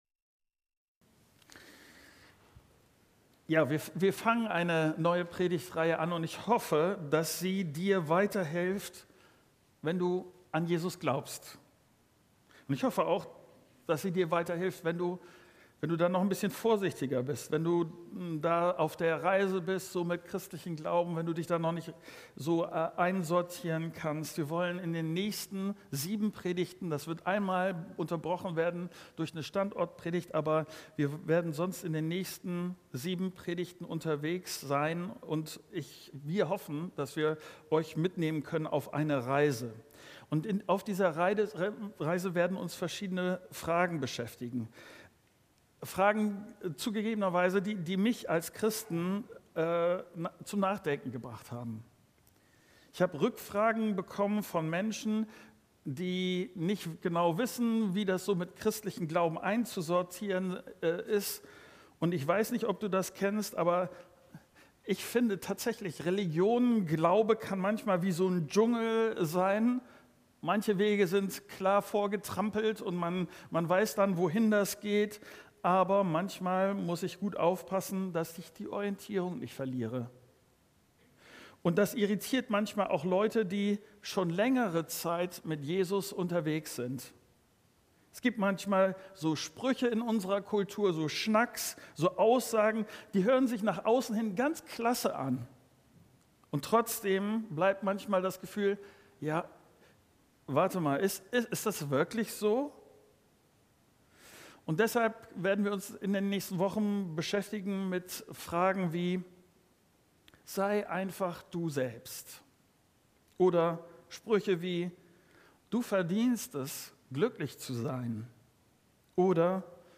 07.09.2025 - Leb deine Wahrheit ~ Predigten der Christus-Gemeinde | Audio-Podcast Podcast